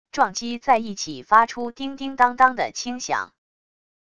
撞击在一起发出叮叮当当的轻响wav音频